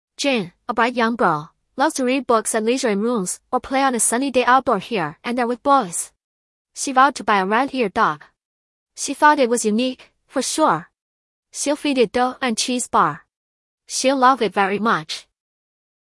Pangram_zh-CN-shaanxi-XiaoniNeural_Jane, a bright.mp3.mp3